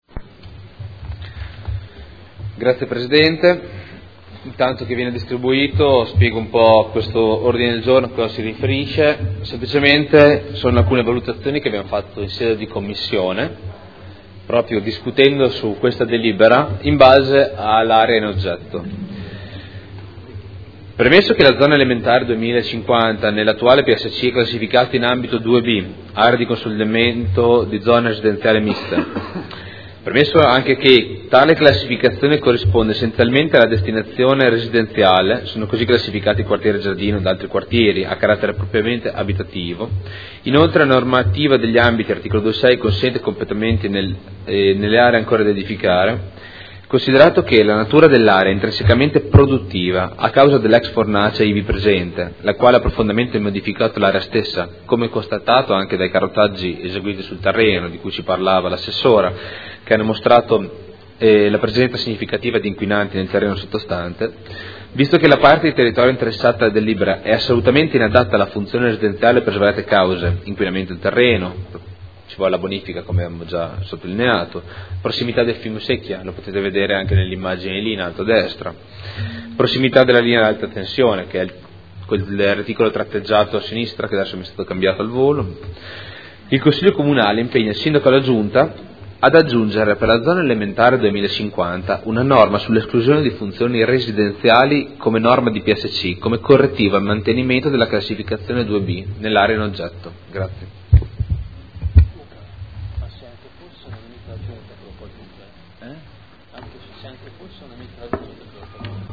Seduta del 30/03/2015. ODG n° 41269 di accompagnamento alla delibera: Variante al Piano Strutturale Comunale (PSC) – Area ubicata tra Tangenziale, strada Ponte Alto e strabello Anesino – Zona elementare n. 2050 Area 01 – Controdeduzioni alle osservazioni e approvazione ai sensi dell’art. 32 della L.R. 20/2000 e s.m.i.